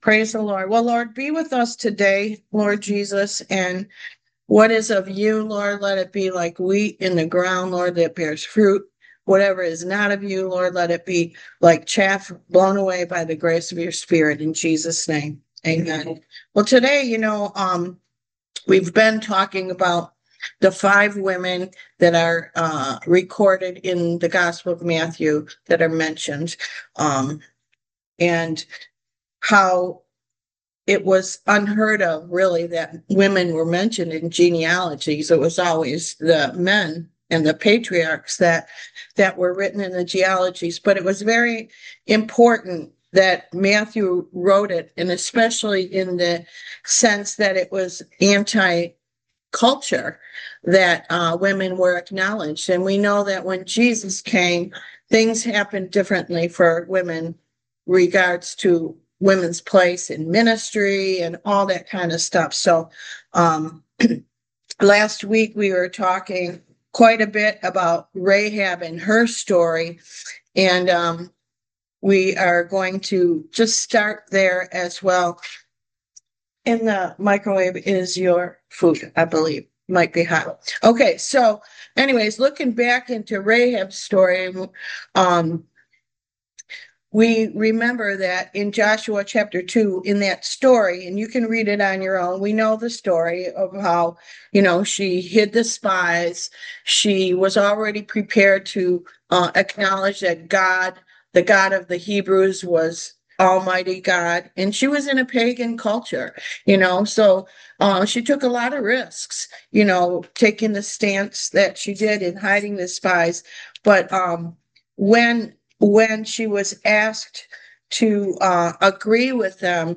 Service Type: Why Jesus Class